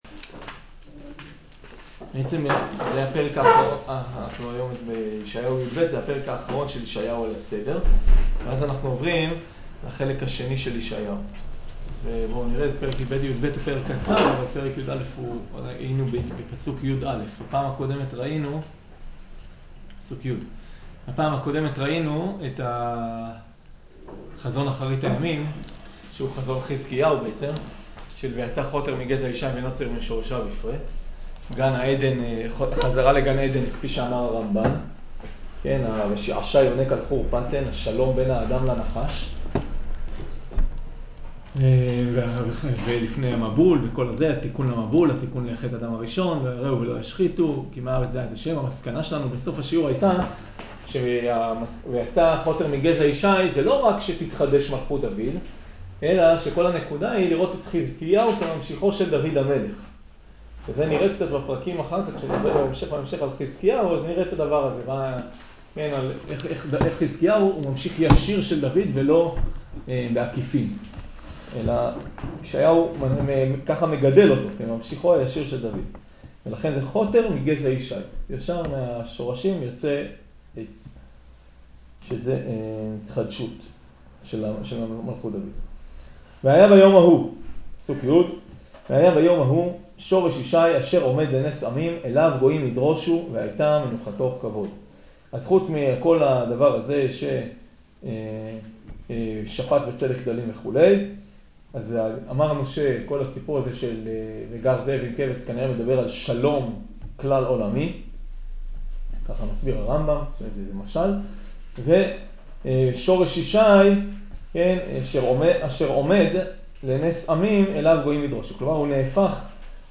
שיעור המשך פרק יא ופרק יב